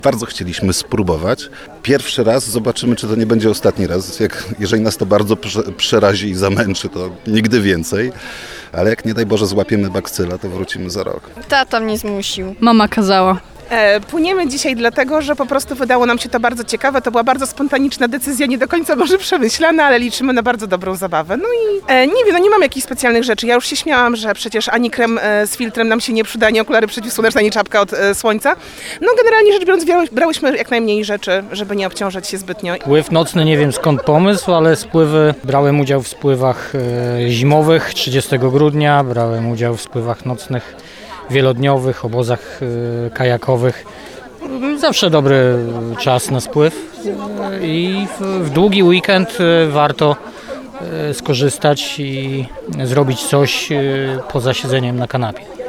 Tradycyjnie już, kajakarze rozpoczęli spływ w Stradunach, przy wspólnym ognisku.